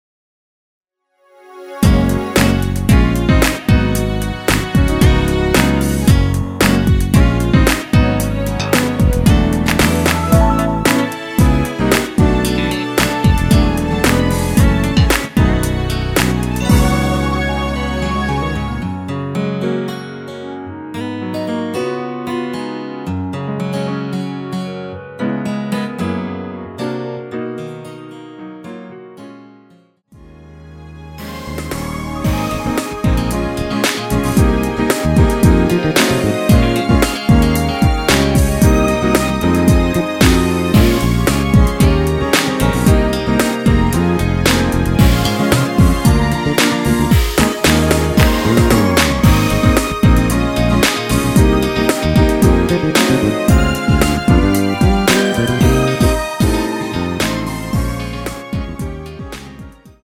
원키에서(-2)내린 멜로디 포함된 MR 입니다.(미리듣기 참조)
Gb
앞부분30초, 뒷부분30초씩 편집해서 올려 드리고 있습니다.
중간에 음이 끈어지고 다시 나오는 이유는